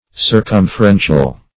Circumferential \Cir*cum`fer*en"tial\, a. [LL.